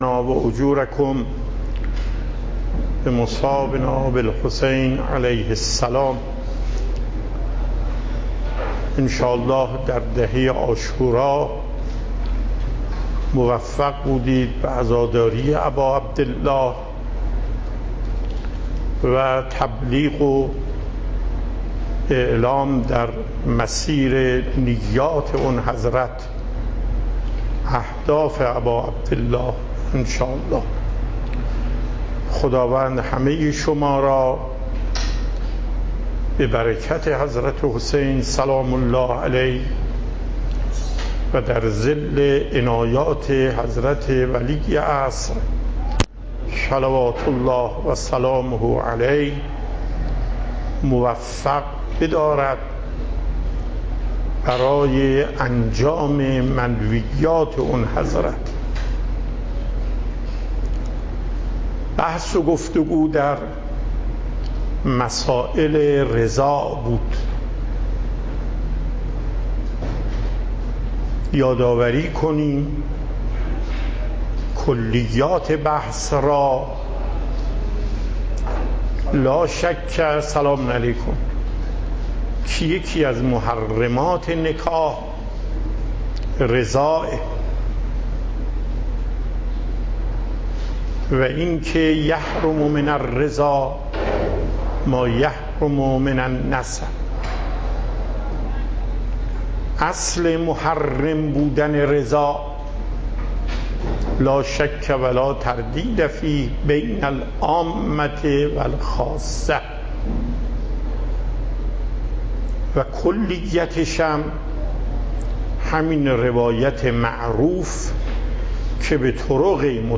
صوت و تقریر درس
درس فقه آیت الله محقق داماد